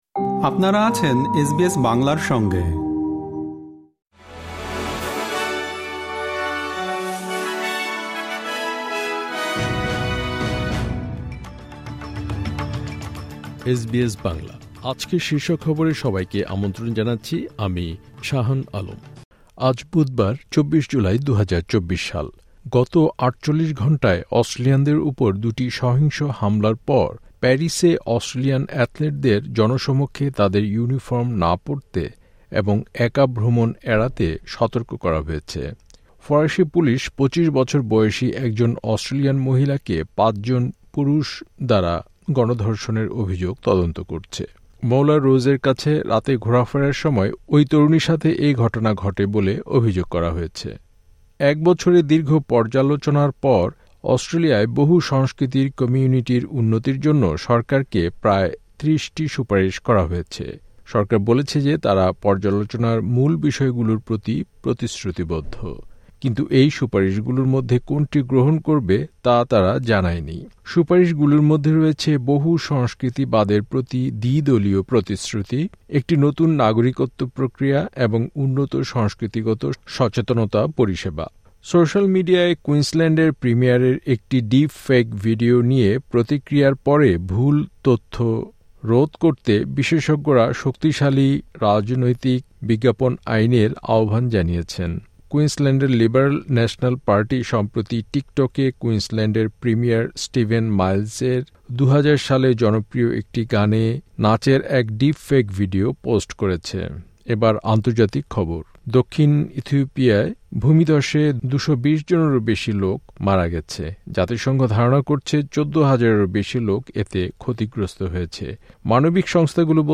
এসবিএস বাংলা শীর্ষ খবর: ২৪ জুলাই, ২০২৪